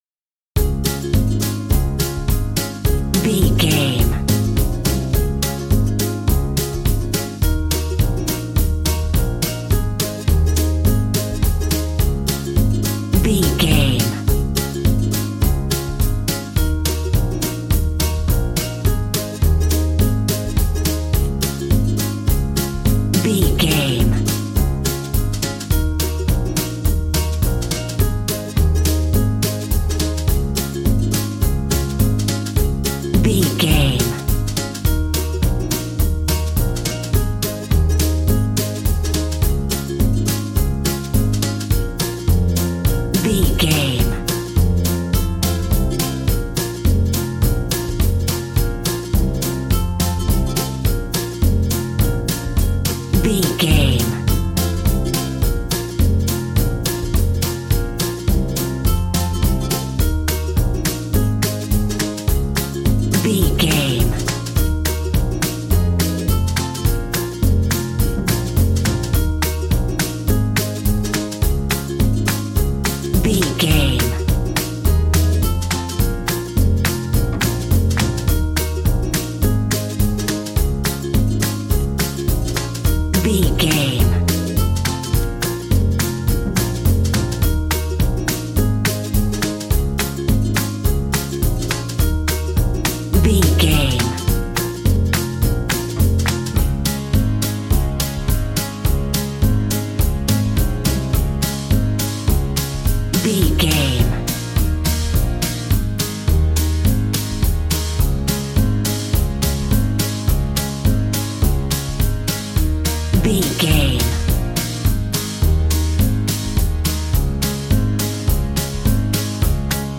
Upbeat, uptempo and exciting!
Aeolian/Minor
cheerful/happy
bouncy
electric piano
electric guitar
drum machine